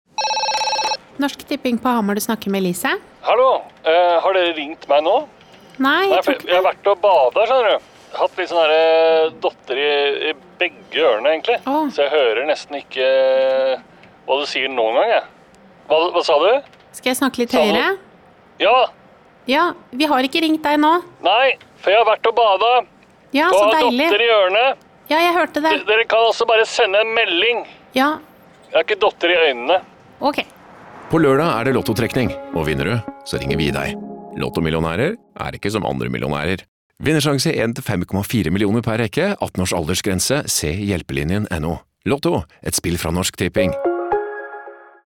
Regi må trekkes frem her og spesielt karakteren Elise er en favoritt: den stadige balanseringen mellom høflig og irritert er nydelig utført.